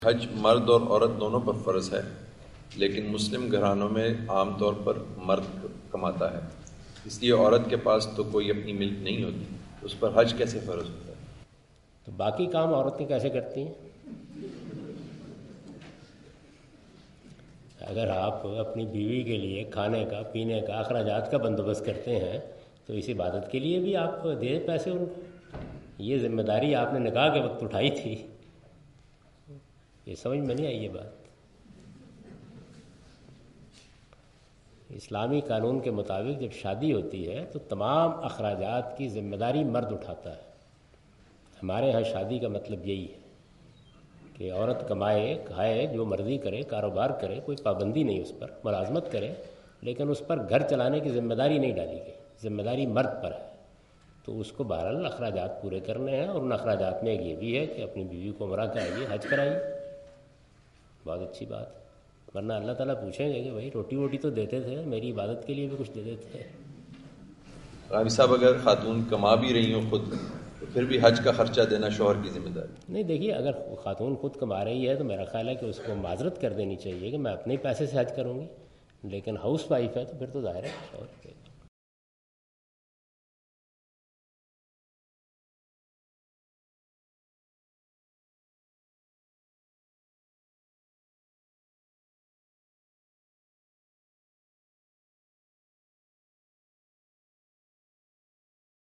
Javed Ahmad Ghamidi answered the question.
جاوید احمد غامدی سوال کا جواب دے رہے ہیں۔